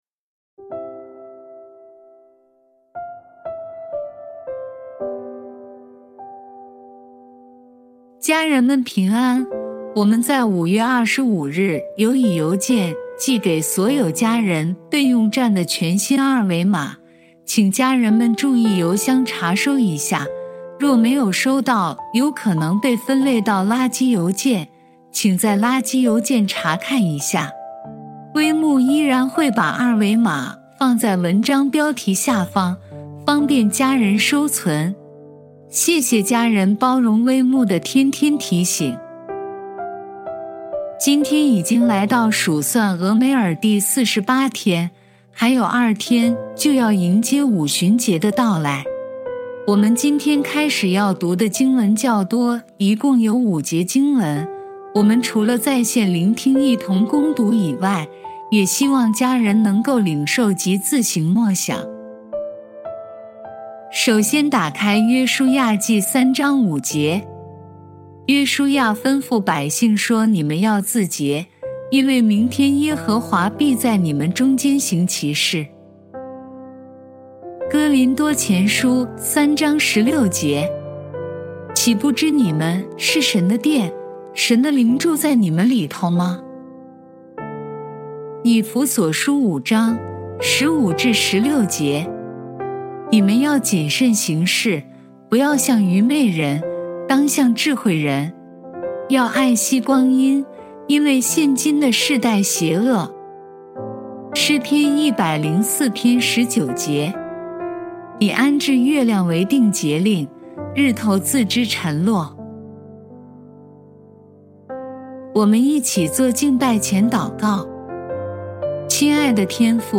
祷告及朗读